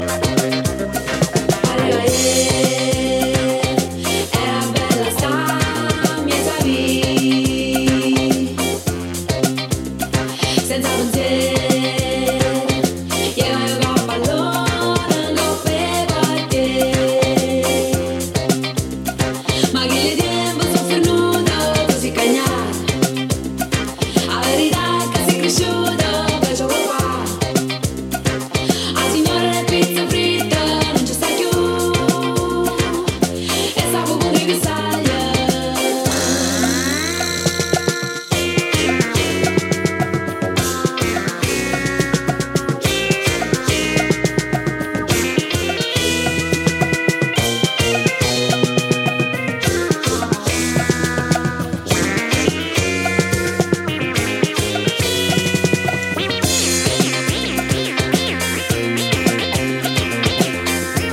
disco, jazz-funk, African rhythms